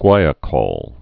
(gwīə-kôl, -kōl)